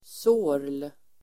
Uttal: [så:r_l]